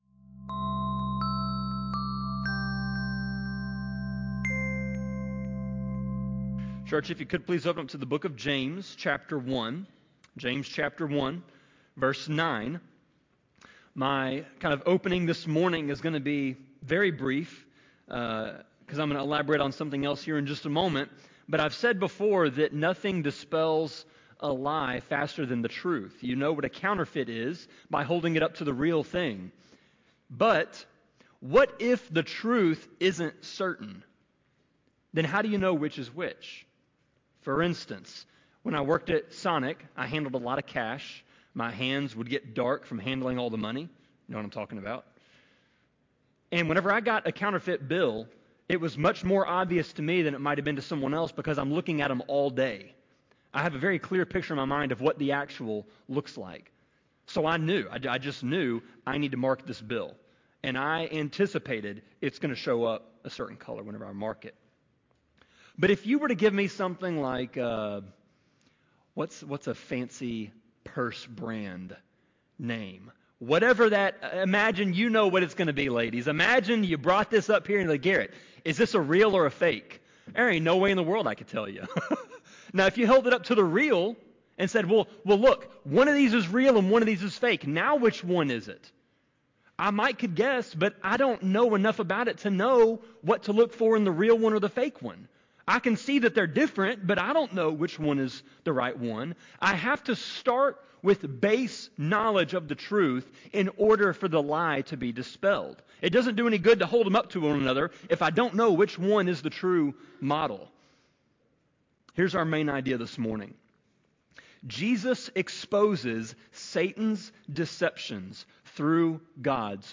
Sermon-25.5.25-CD.mp3